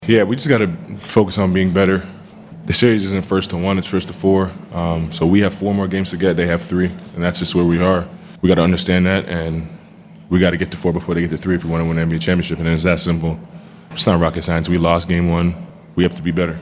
SGA postgame says the goal is clear moving forward.
SGA postgame 6-6.mp3